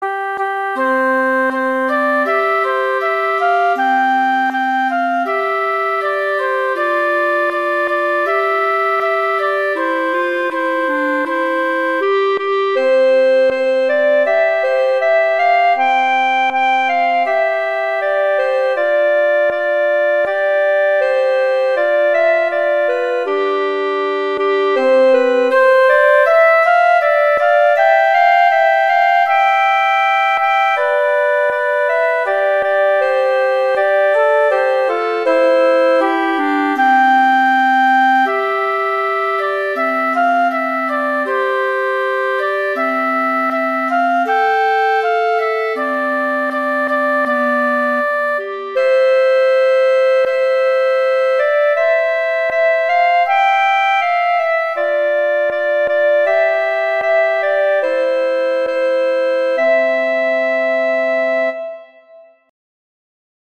Instrumentation: flute & clarinet
C major
♩=80 BPM